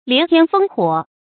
連天烽火 注音： ㄌㄧㄢˊ ㄊㄧㄢ ㄈㄥ ㄏㄨㄛˇ 讀音讀法： 意思解釋： 烽火：古時邊防報警的煙火，比喻戰火或戰爭。